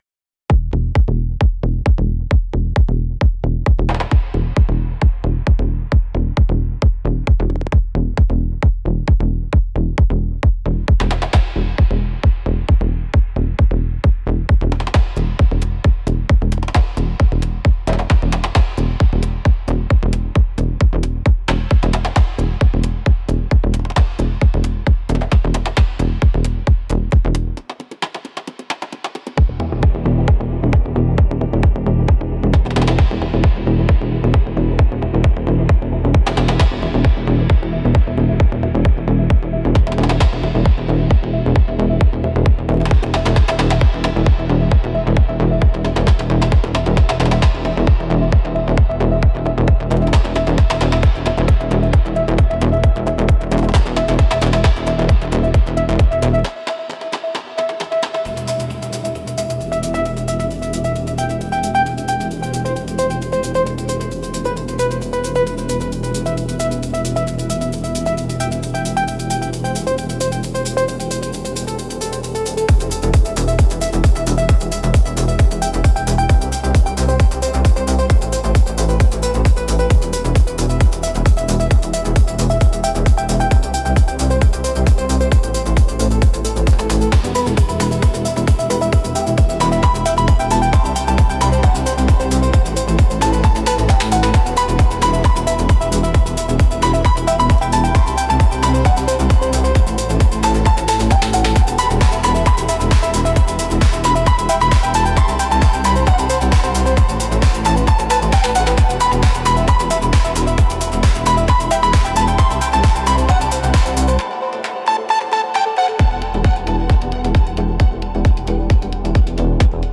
Instrumentals - Ritual Glitch